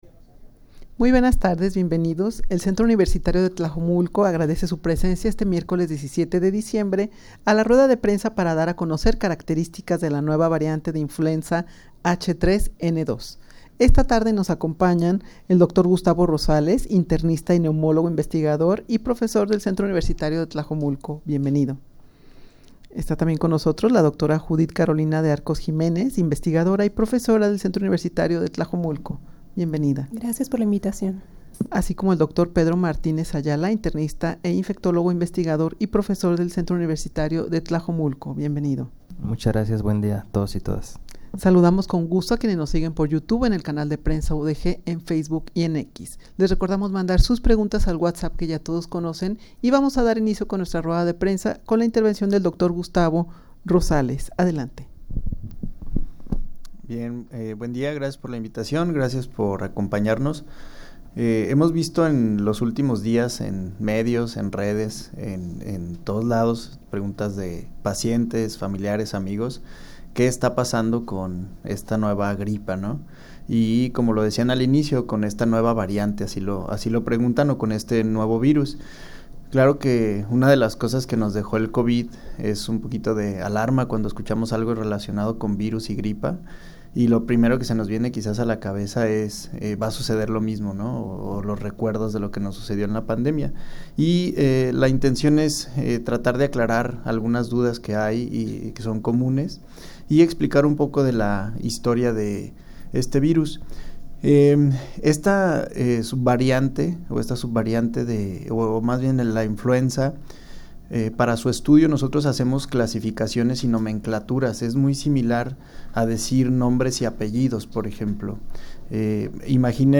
Audio de la Rueda de Prensa
rueda-de-prensa-para-dar-a-conocer-caracteristicas-de-la-nueva-variante-de-influenza-h3n2.mp3